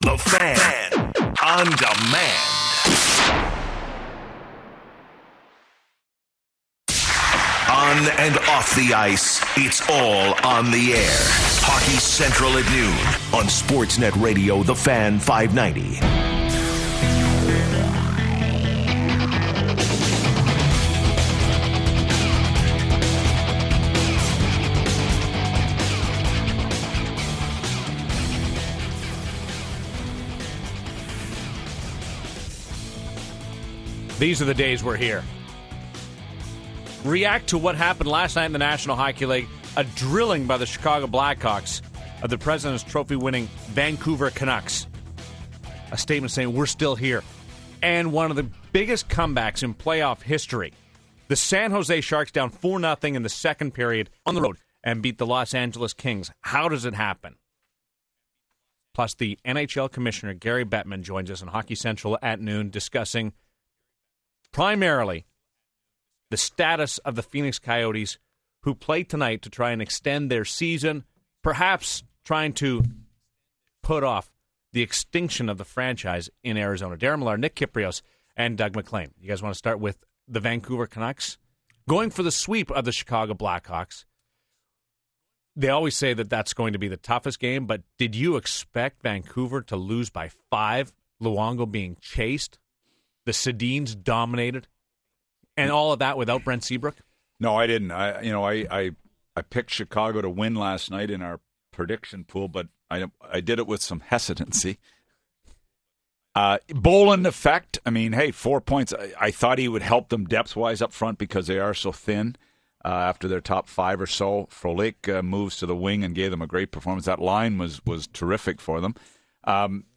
But he said the league continues to try to work it out in Glendale. Listen to Bettman interview here .